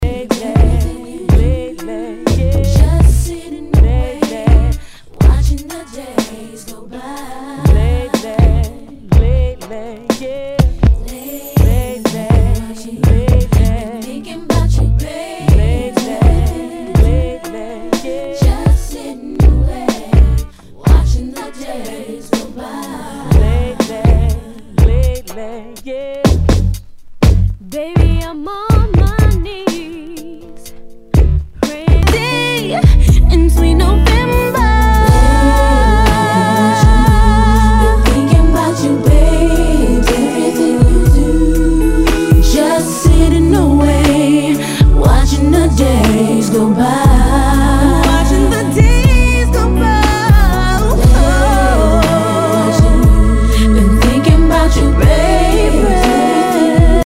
HIPHOP/R&B
ナイス！R&B！